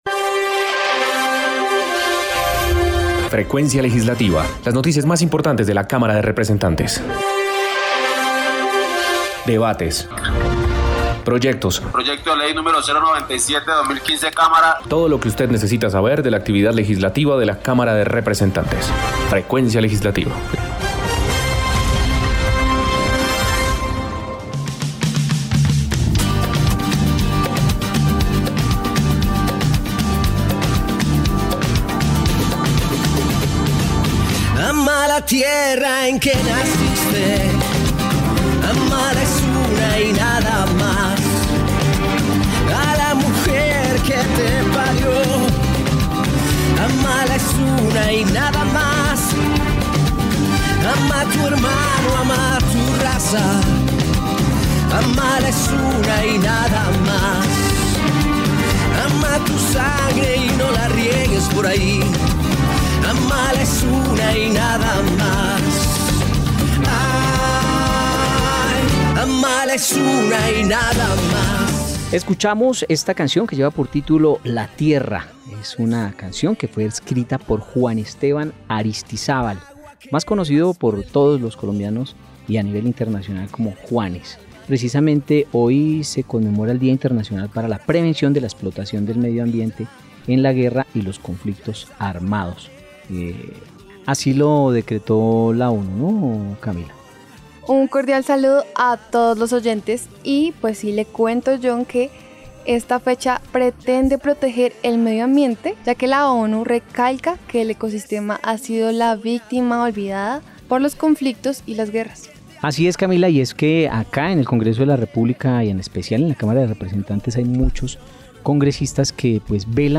Programa Radial Frecuencia Legislativa.